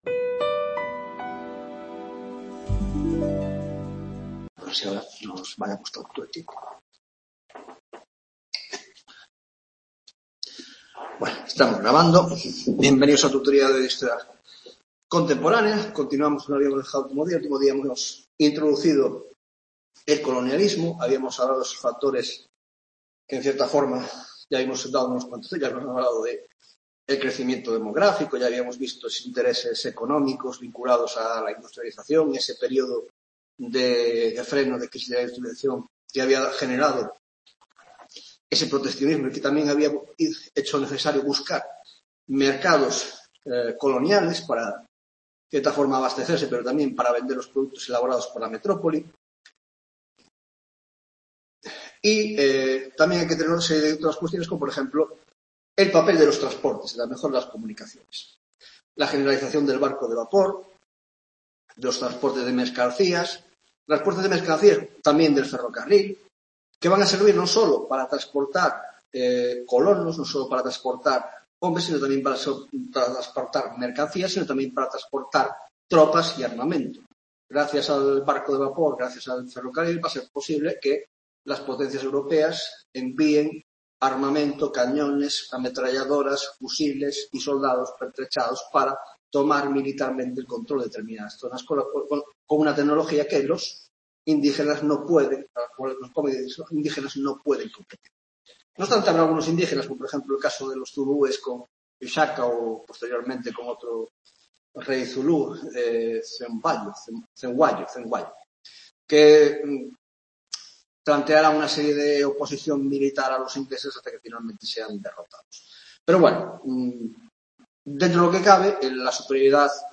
15 tutoría de Historia Contemporánea